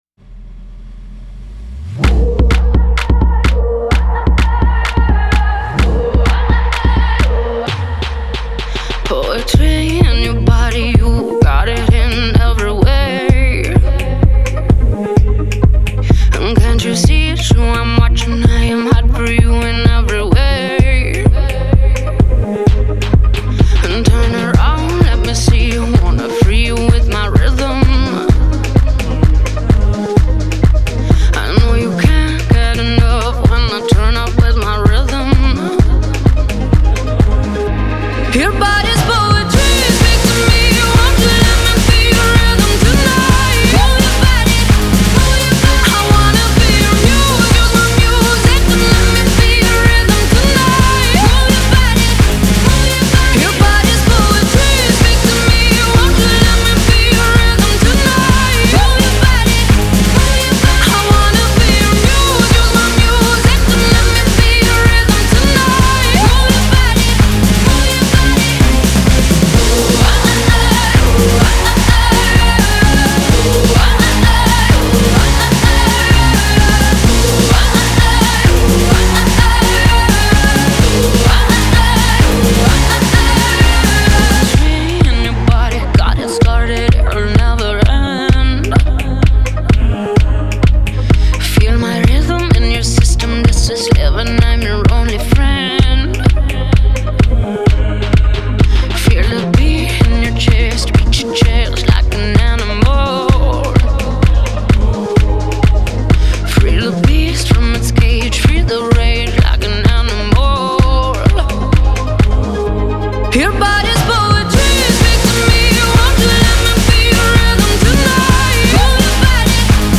Genre: Alternative, Pop